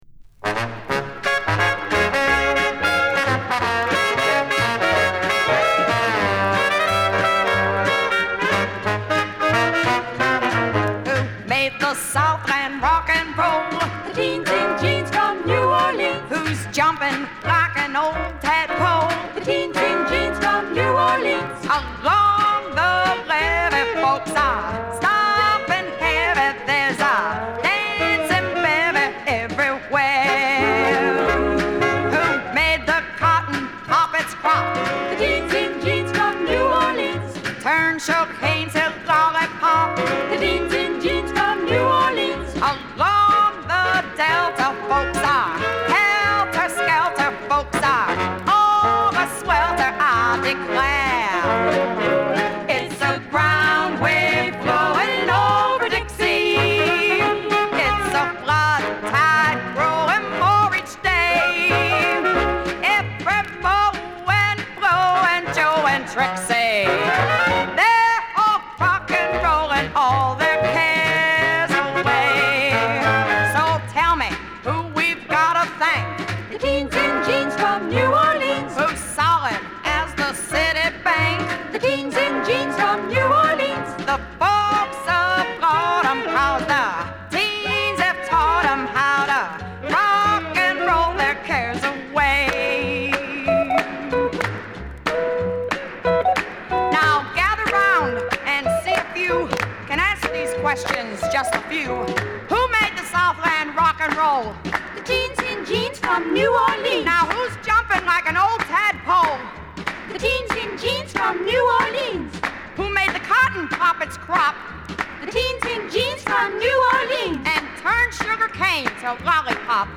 ノヴェルティ調のミッドテンポ・ロッカー。ヴォーカルの力強さ、威勢の良さは相変わらず。